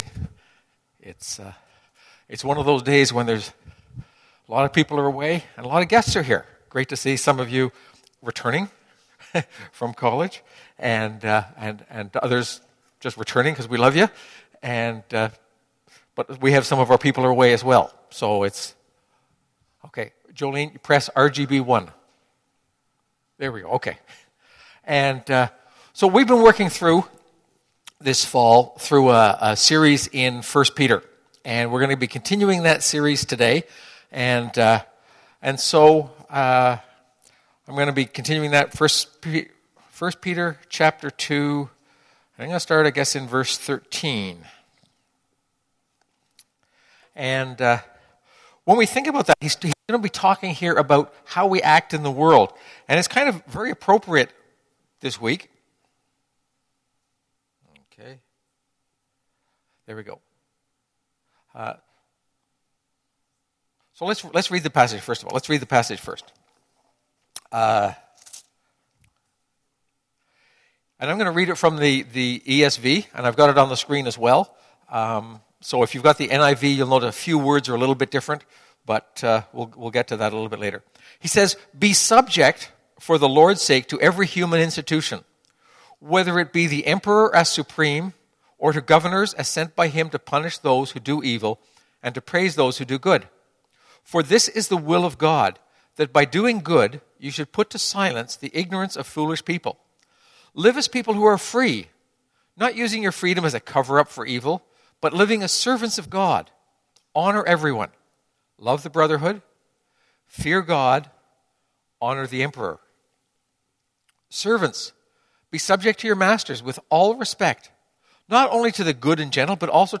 This sermon is based on 1 Pet 2:11-25.